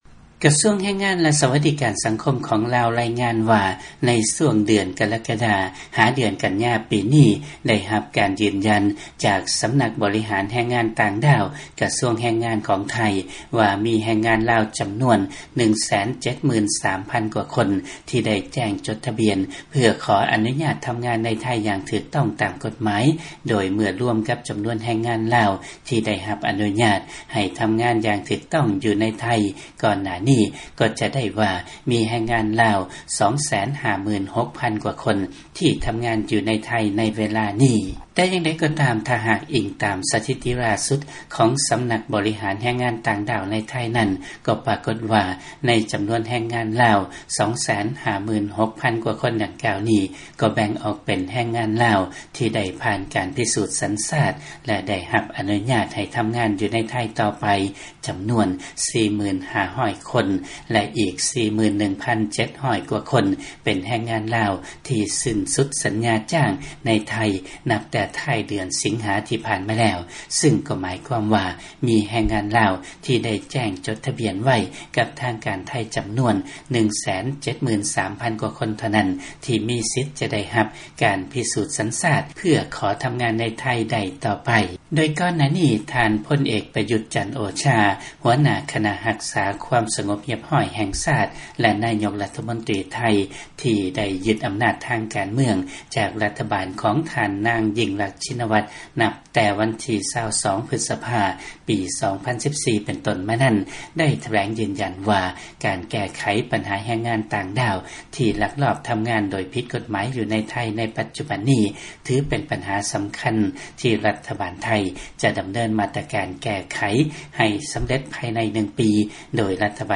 ຟັງລາຍງານ ລາວລາຍງານວ່າ ມີແຮງງານລາວ 256,000 ກວ່າຄົນເຮັດວຽກຢູ່ໄທ ໃນປັດຈຸບັນ.